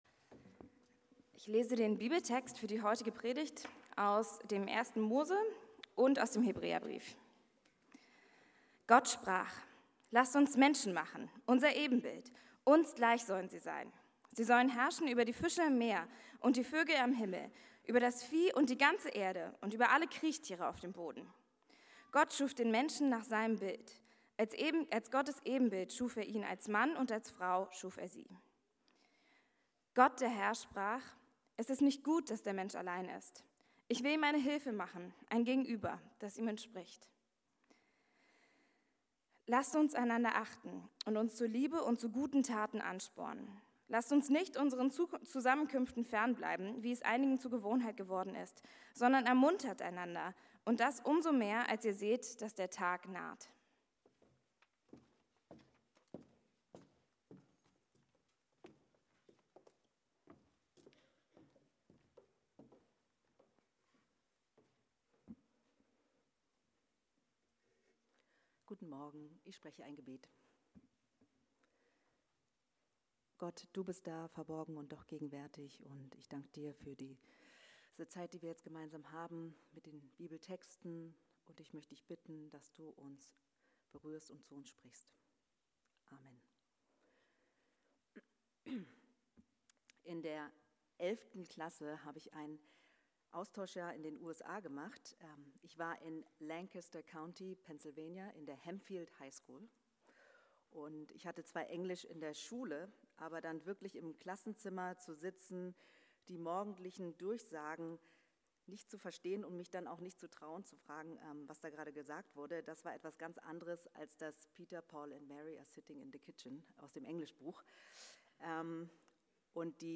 Für Gemeinschaft gemacht ~ Berlinprojekt Predigten Podcast
09.11.berlinprojekt-predigtmp3.mp3